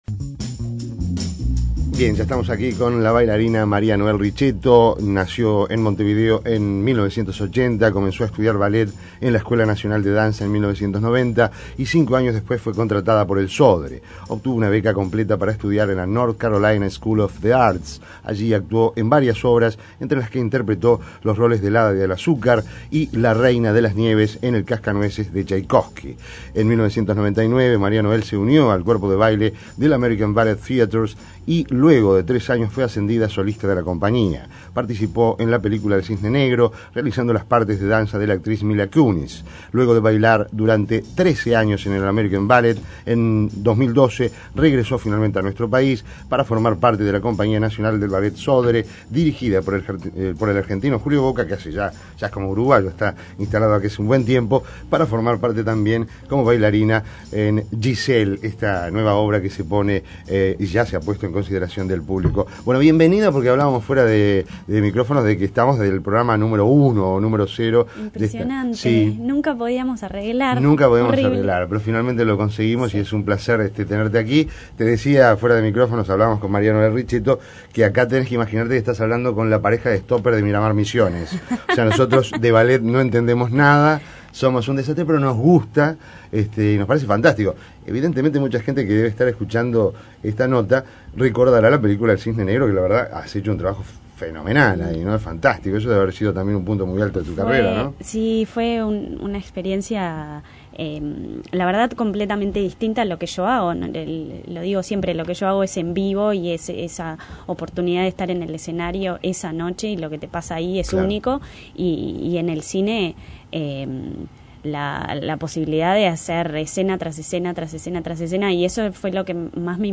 Descargar Audio no soportado Entrevista Maria Noel Riccetto